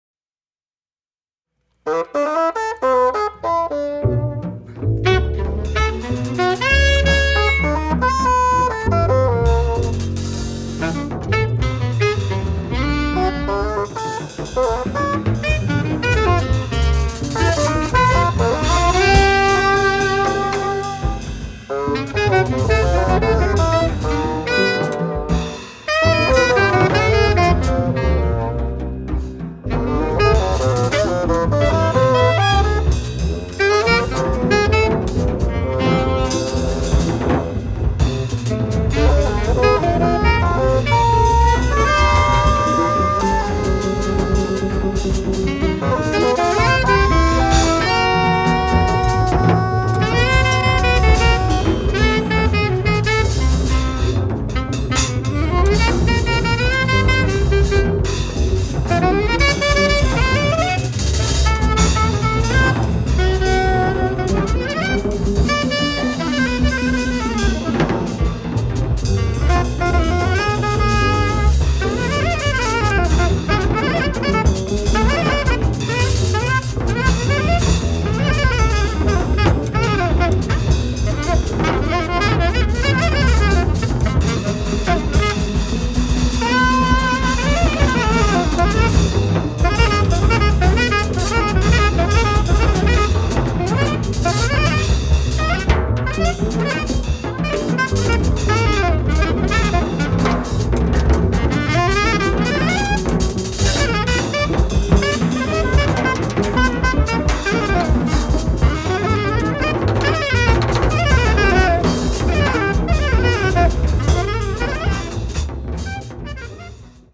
free jazz and improvised music
alto saxophone
bassoon
bass
drums
• Track 1 recorded May 21, 1976 at the Wildflowers festival